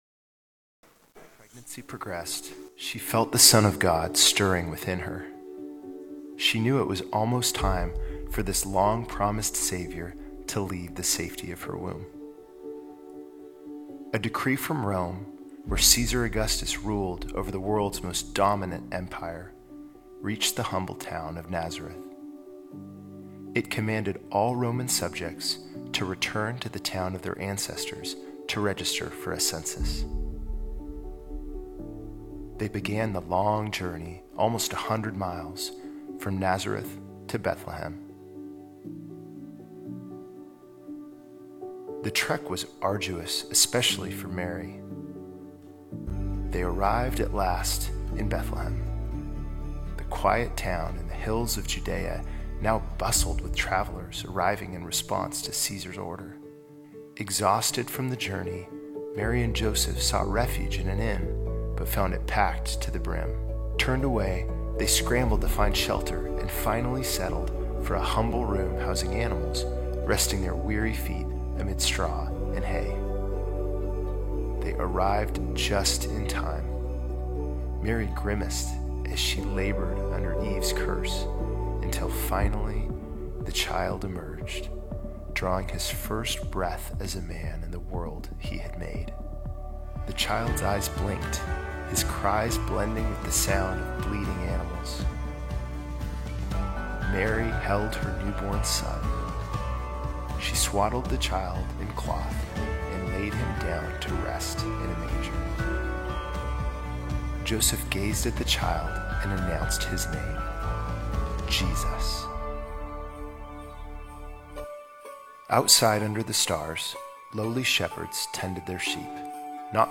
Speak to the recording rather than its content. This is part of our all-age service.